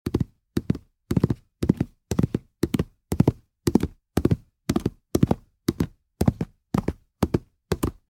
Horse Galloping Téléchargement d'Effet Sonore
Horse Galloping Bouton sonore